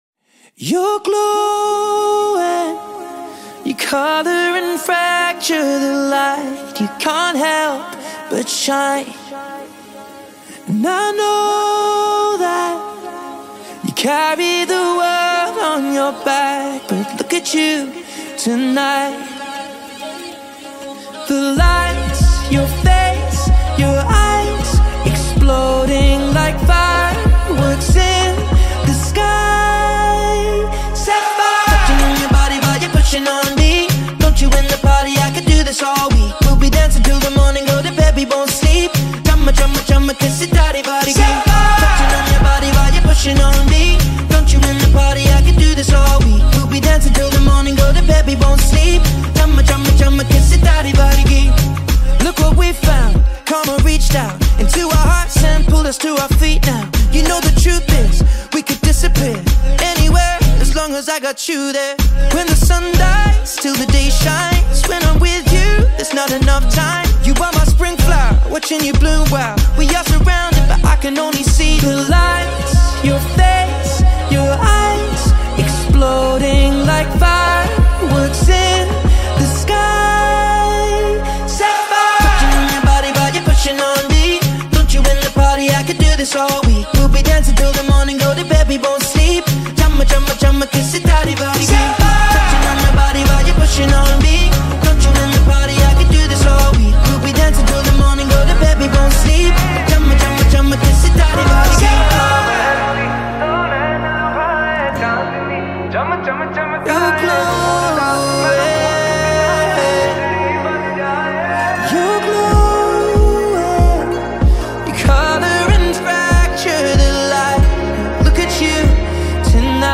cross-cultural remix